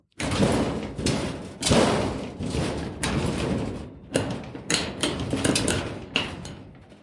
金属 " 金属自由长
描述：金属隆隆声，撞击声和刮擦声。
Tag: 光泽 铁匠 金属 工业 钢铁 隆隆声 指甲 命中 打击乐器 金属 刮去 工厂 冲击 锁定 工业